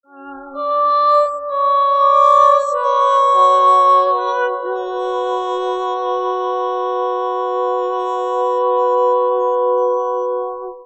voice (part missing 1,6s) .wav reconstructed using temporal AR interpolation .wav
reconstructed using AR interpolation of sinusoidal parameters
proverb1_cont_cut_200_v.wav